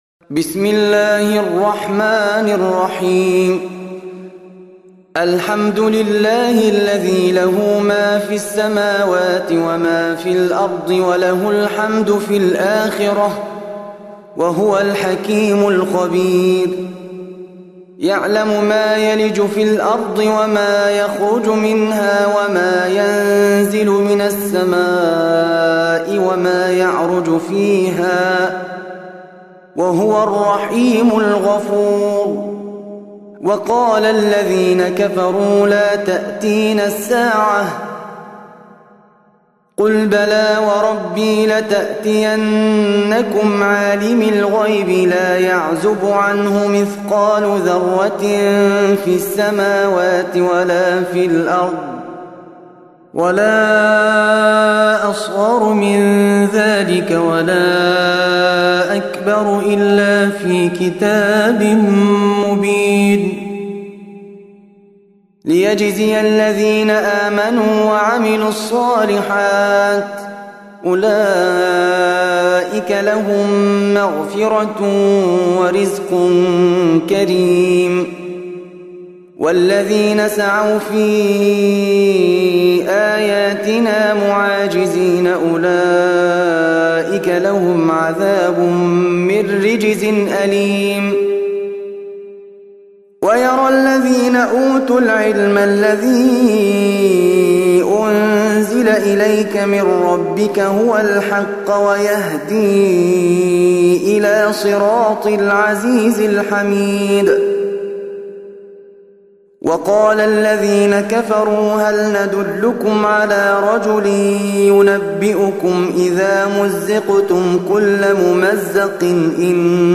34. Surah Saba' سورة سبأ Audio Quran Tarteel Recitation
Surah Repeating تكرار السورة Download Surah حمّل السورة Reciting Murattalah Audio for 34.